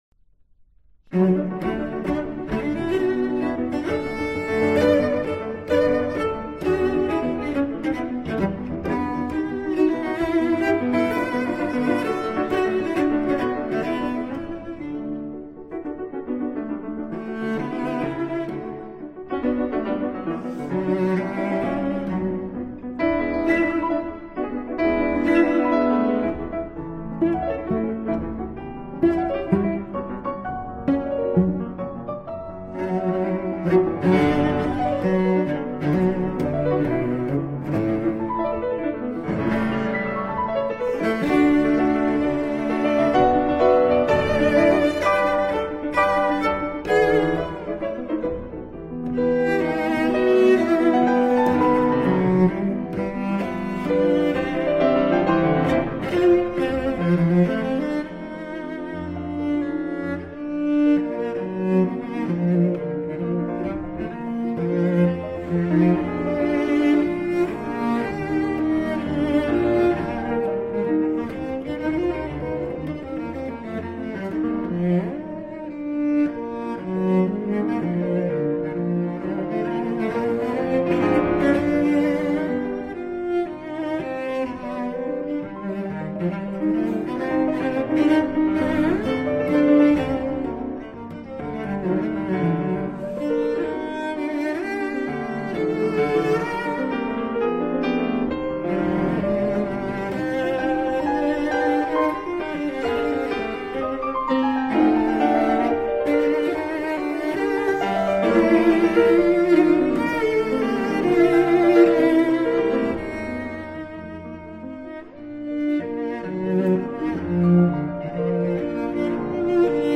Soundbite 3rd Movt
Sonata for Cello and Piano in D Major, Op.17